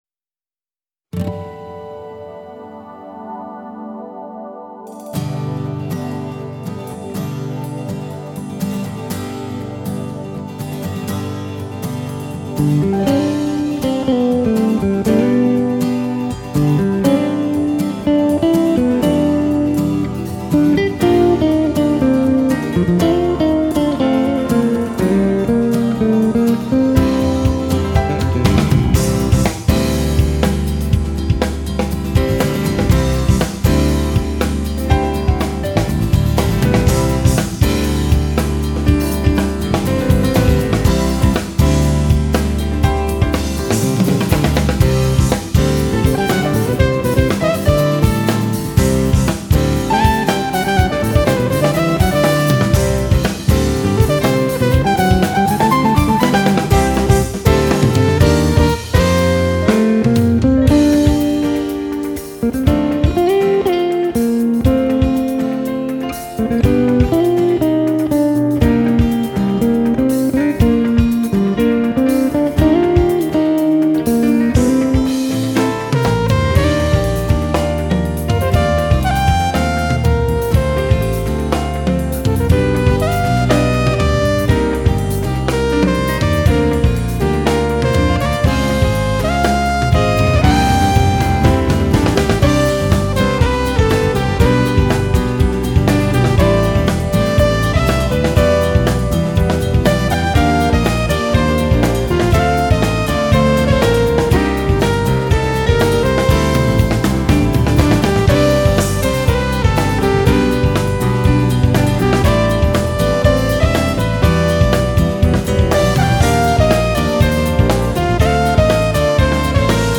6-Strings Bass
EWI
Drums
Acoustic Guitar
Piano